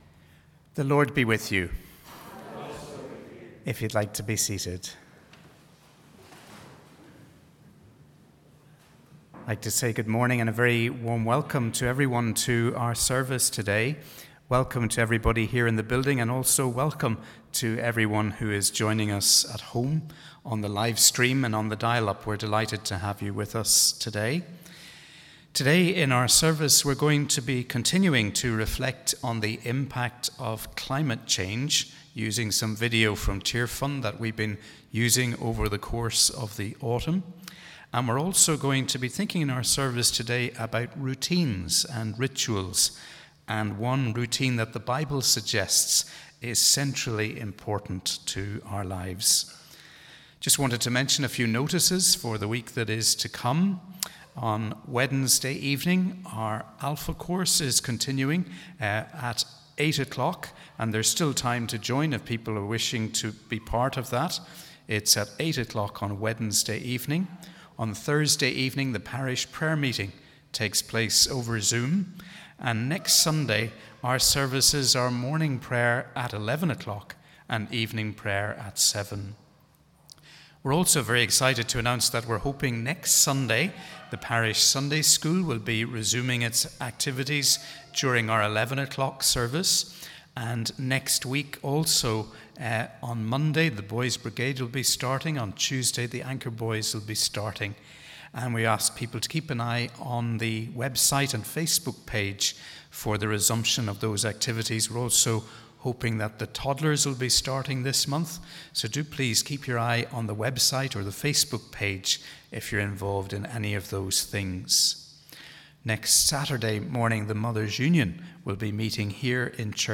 Wherever you are, we warmly welcome you to our service for the 17th Sunday after Trinity.